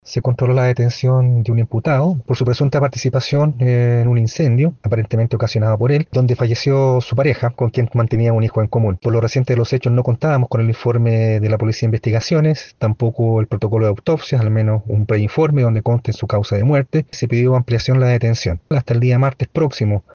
El fiscal jefe de Villa Alemana, Osvaldo Basso, explicó las razones de la postergación de la audiencia —a realizarse a las 10:30 horas del martes— aclarando que con los informes pendientes, se podrá determinar si se imputan o no los cargos.